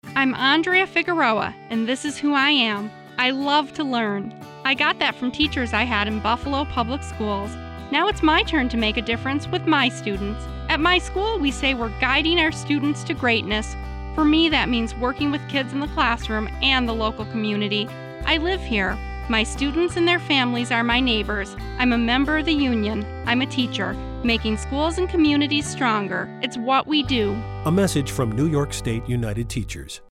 statewide radio advertisement